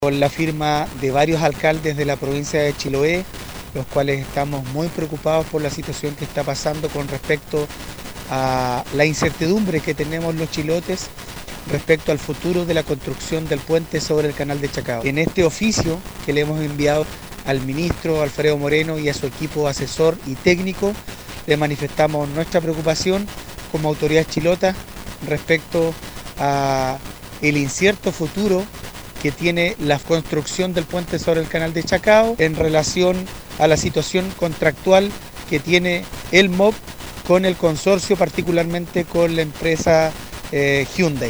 Por otra parte no descartaron viajar a Santiago para solicitar una audiencia con representantes del Gobierno, según afirmó el alcalde de Quéilen, Marcos Vargas.